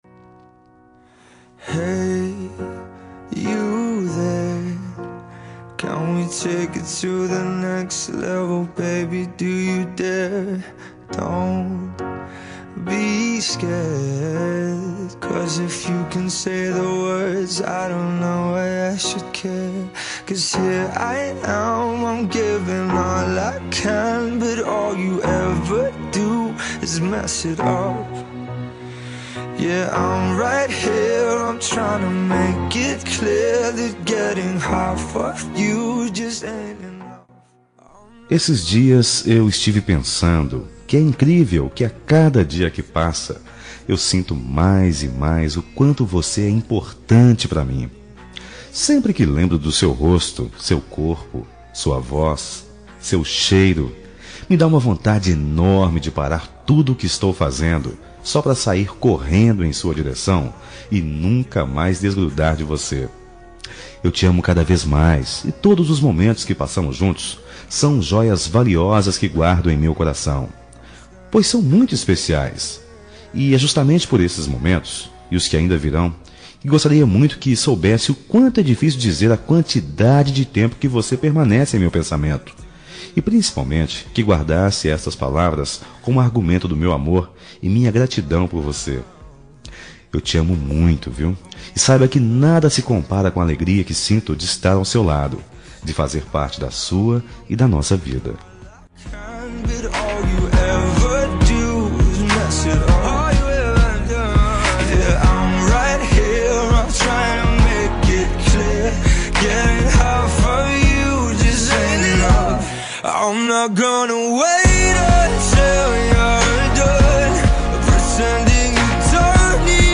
Telemensagem Romântica GLS – Voz Masculina – Cód: 5486 – Linda
5486-gls-rom-masc.m4a